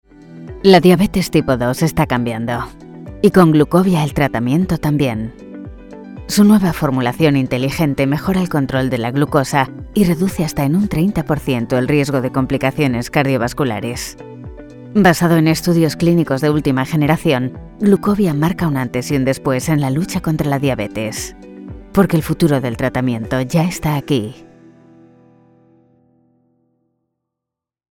Explainer Videos
Castilian Spanish online voice over artist fluent in English.
Soundproof recording booth ( studiobricks)
Mic Neumann U87 Ai